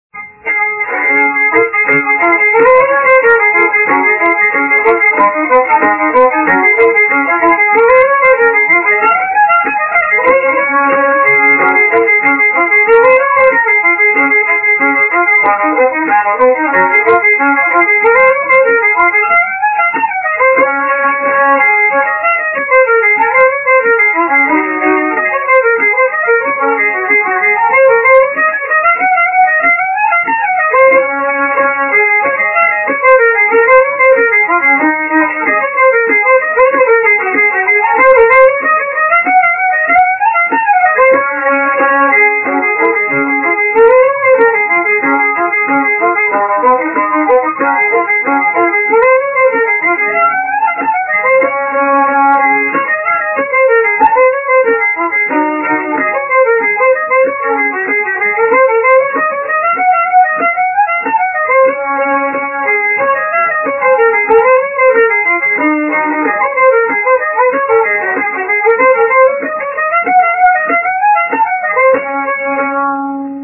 Granddad fiddled and he wrote music.
The recordings were originally cut directly into 78 RPM disks. They have passed through a cassette tape version and some amateur editing to reach their current MP3 form.
Trust me, the frequency range of the original recording has not been compromised.